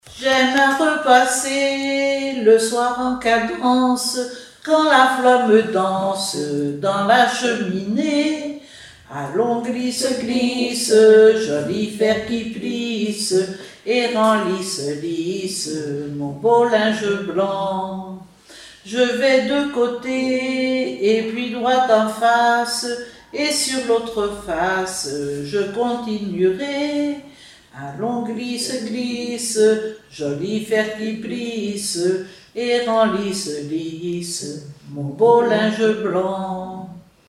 circonstance : éducation scolaire
Genre strophique
Chansons et formulettes enfantines
Pièce musicale inédite